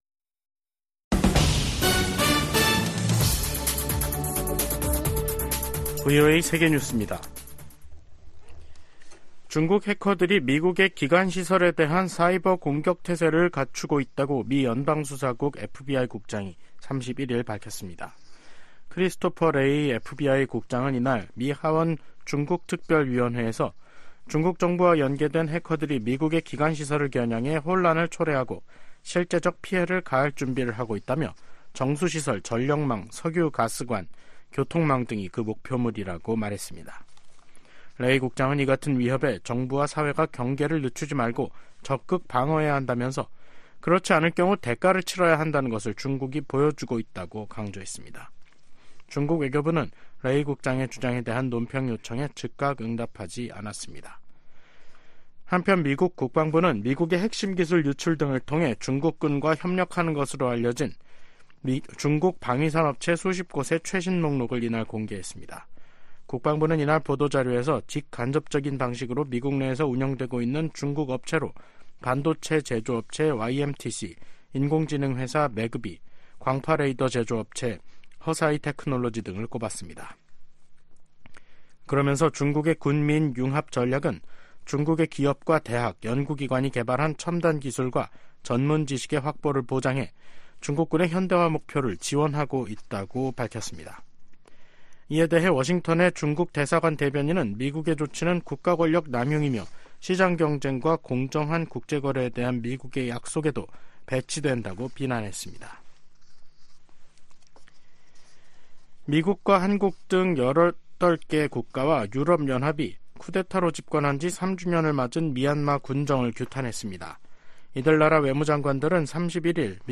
VOA 한국어 간판 뉴스 프로그램 '뉴스 투데이', 2024년 2월 1일 3부 방송입니다. 한국 총선을 겨냥한 북한의 도발 가능성에 한반도 긴장이 고조되고 있습니다. 미 국무부는 최근 중국 외교부 대표단의 방북 직후 북한이 순항미사일을 발사한 점을 지적하며, 도발을 막는데 중국의 역할이 필요하다고 강조했습니다. 미한일 협력이 북한·중국 대응을 넘어 세계 현안을 다루는 협력체로 성장하고 있다고 백악관 국가안보보좌관이 말했습니다.